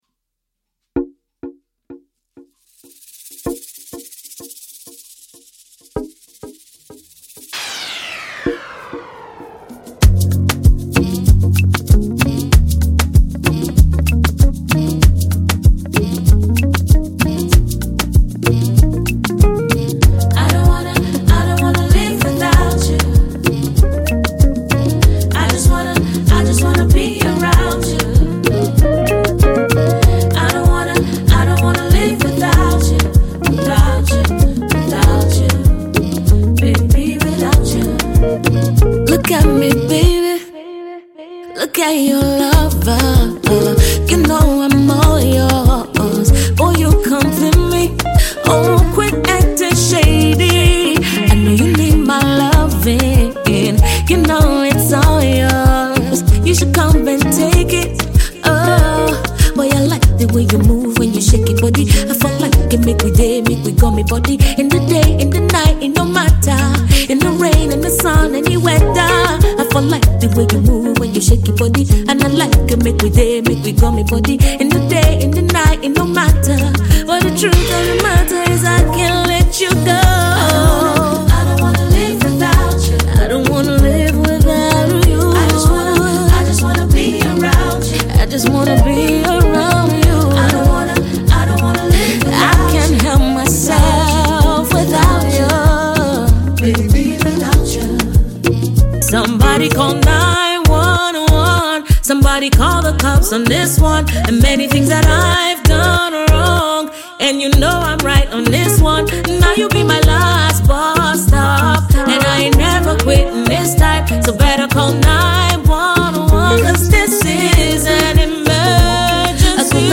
R&B diva